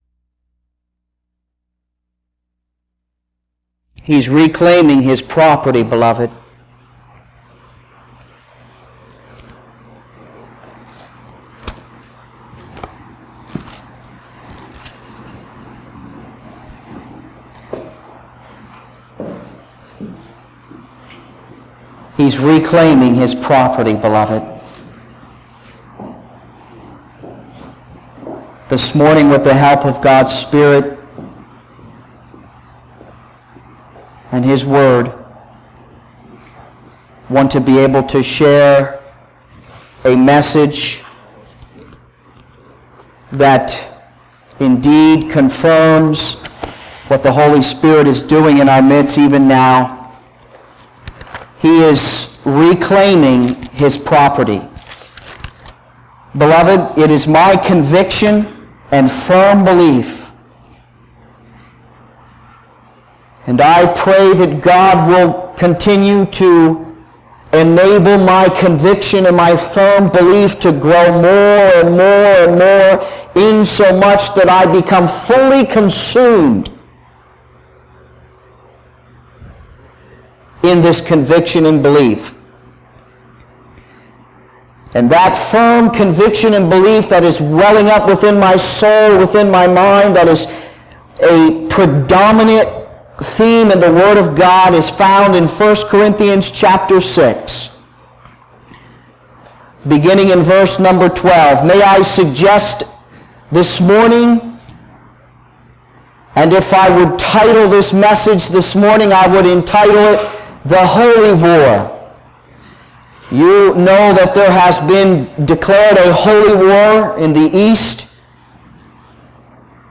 The sermon calls for a transformation in thinking and living, urging believers to yield to the Holy Spirit and embrace their identity in Christ to experience true victory over sin.